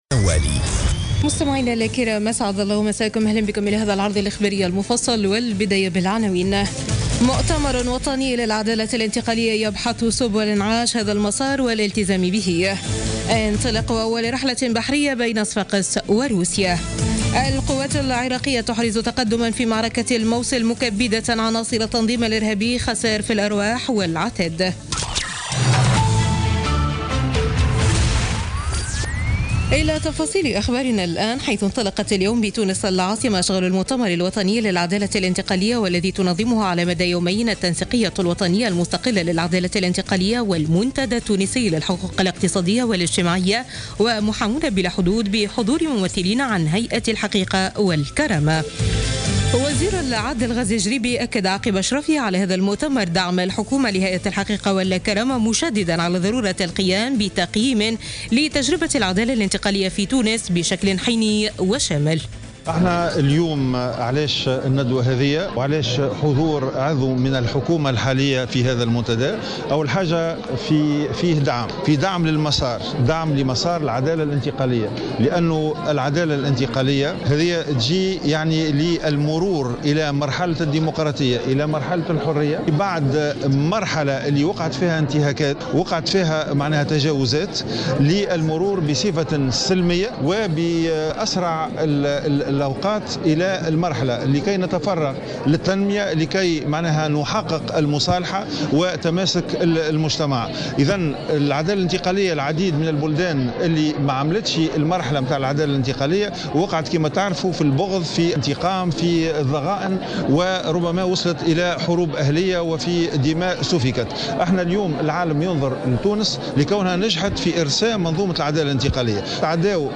Journal Info 19h00 du mercredi 2 novembre 2016